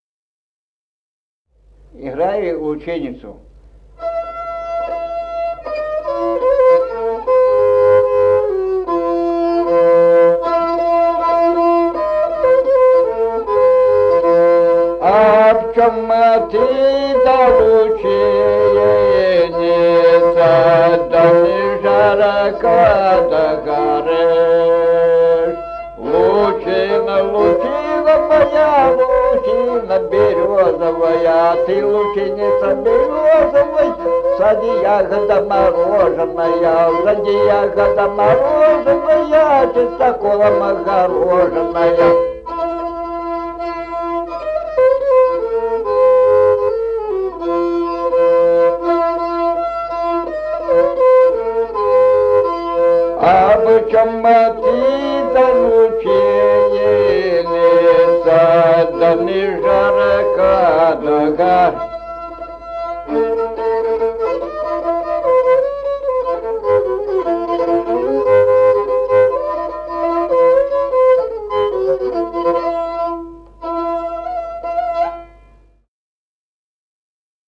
Музыкальный фольклор села Мишковка «Лучина, ты лучиница», лирическая, репертуар скрипача.